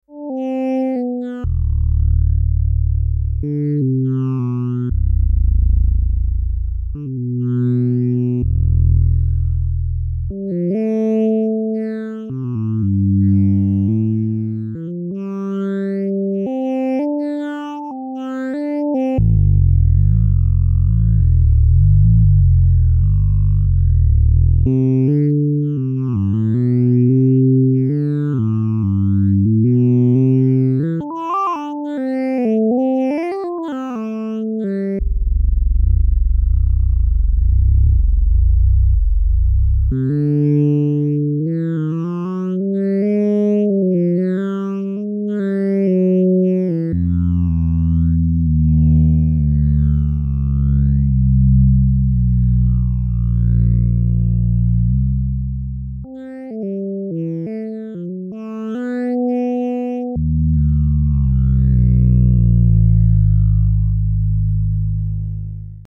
Cette composition musicale aléatoire accompagne l'expérience visuelle de "Courbure", évoquant la tension et la fluidité des formes numériques.